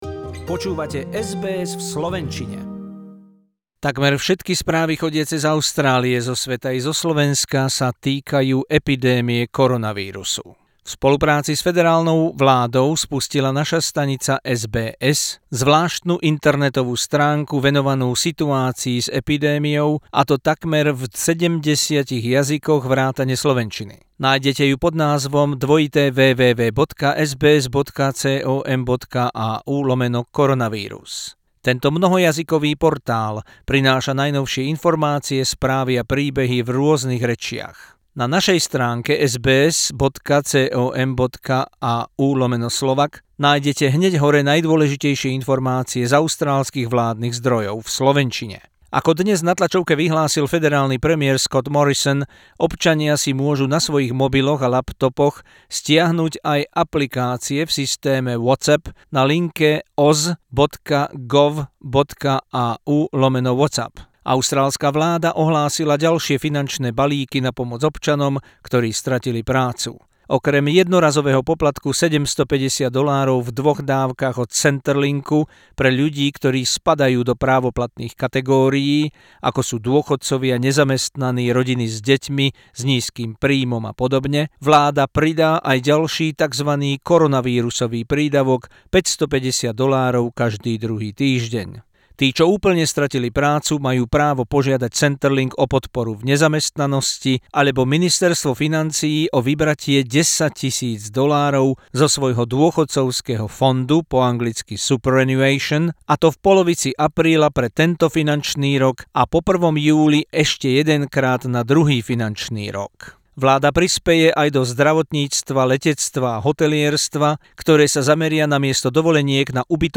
News bulletin in Slovak language on SBS Radio Australia from Sunday 29th March 2020, including extensive coverage of COVID-19.